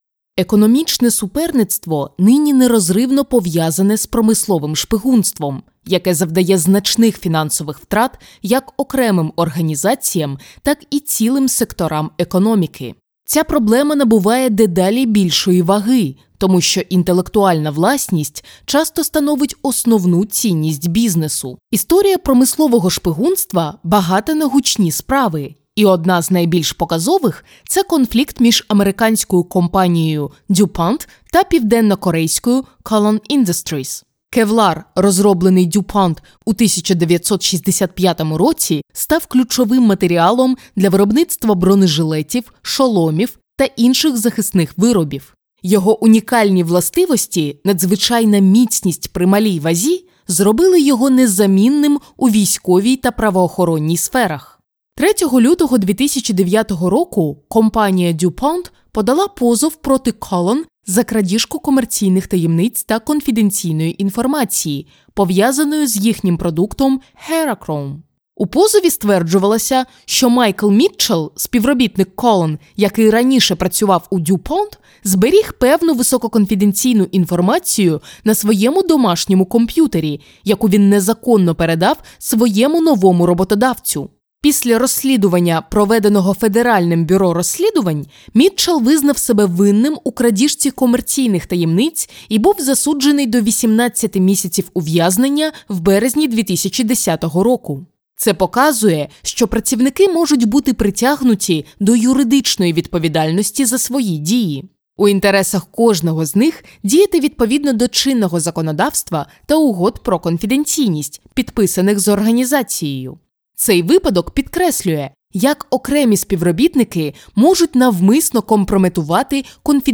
Diep, Natuurlijk, Veelzijdig
E-learning